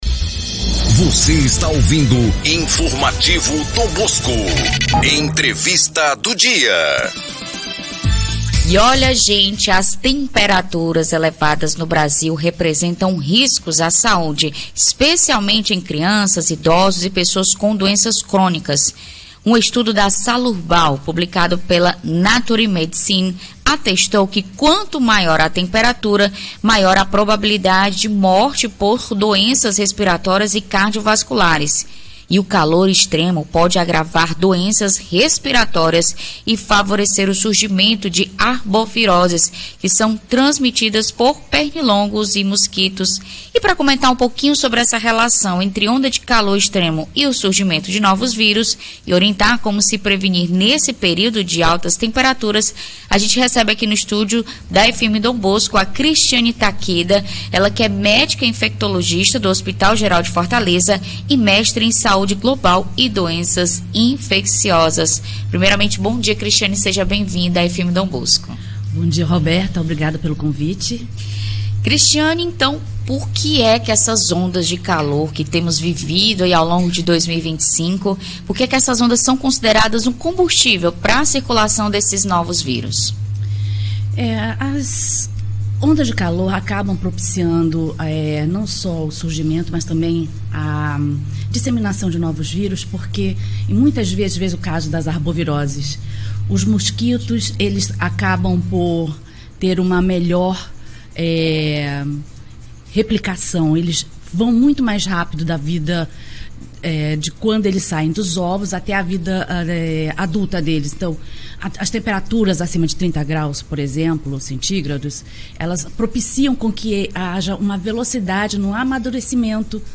Altas temperaturas elevam riscos à saúde e exigem atenção da população; confira entrevista
ENTREVISTA-2912.mp3